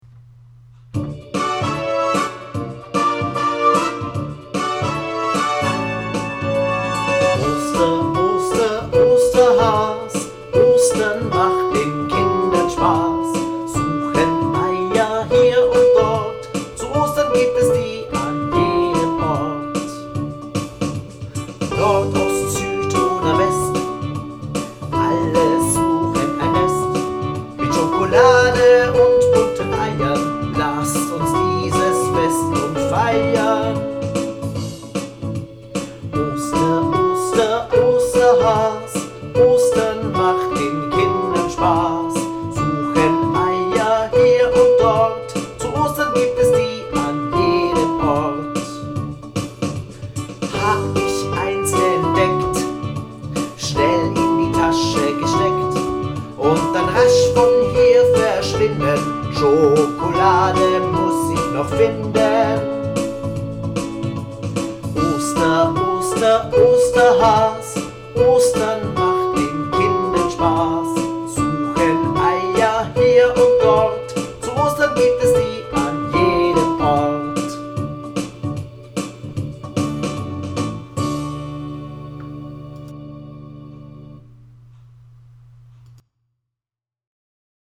Gattung: Lied
Ein einstimmiges Osterlied inklusive Instrumentalbegleitung